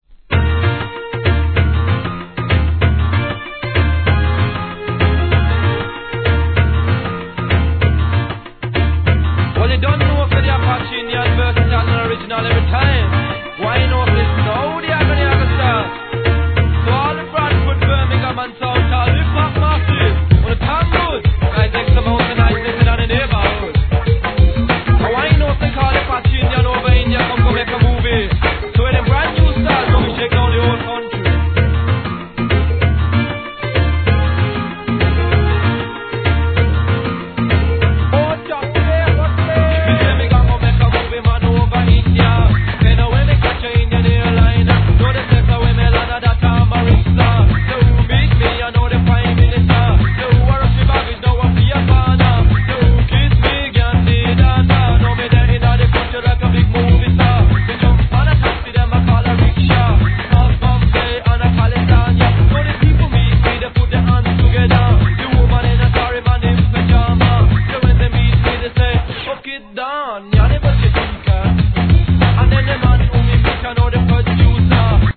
REGGAE
ラガHIP HOP!!